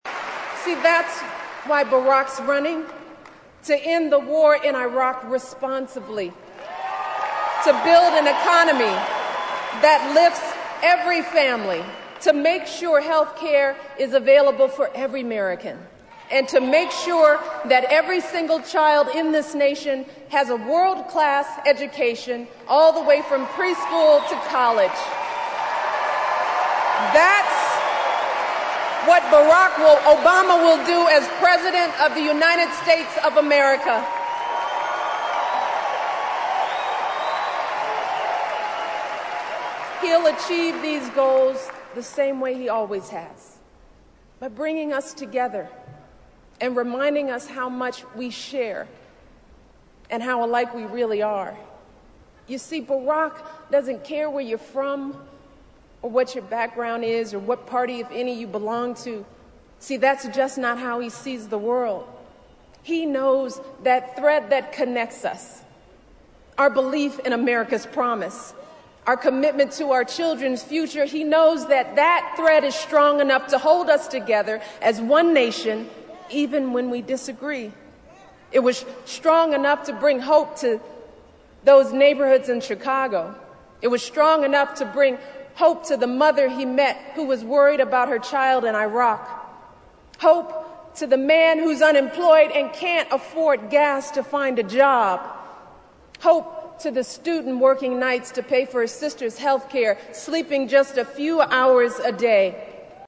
名人励志英语演讲 第95期:让我们选举巴拉克·奥巴马为美利坚合众国总统(8) 听力文件下载—在线英语听力室